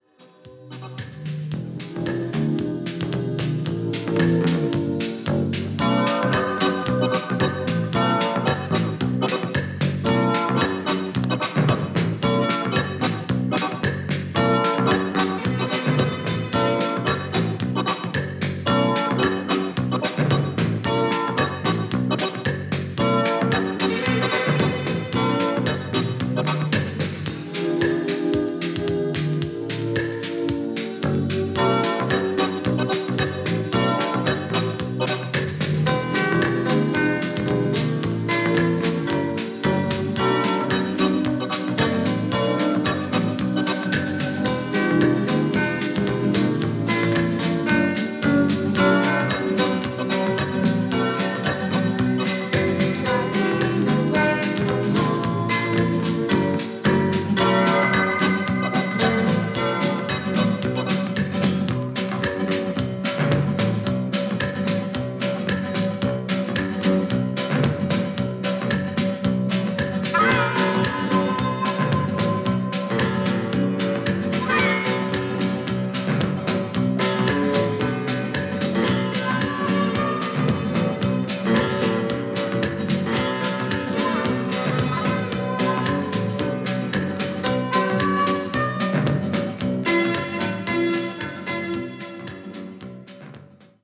ドラマチックな楽曲でハマっちゃいます！！！